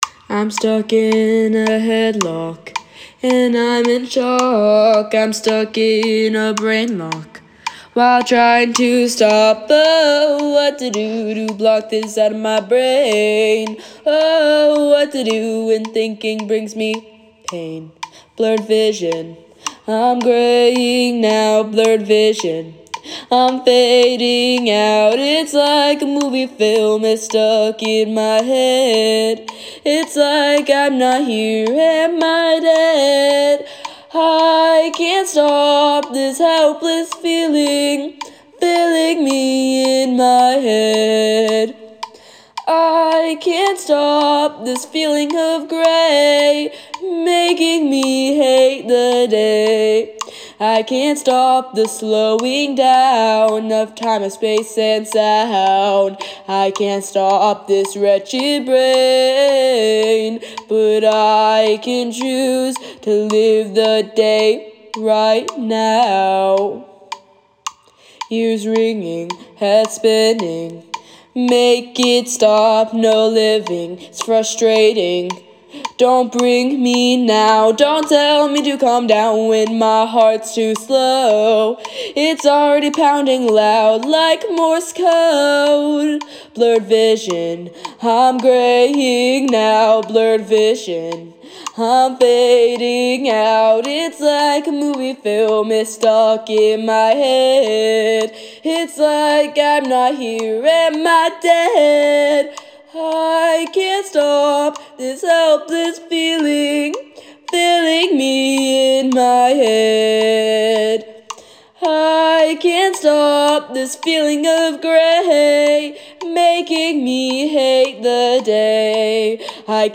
An experimental, mostly piano, song that portrays panic or overload, does get loud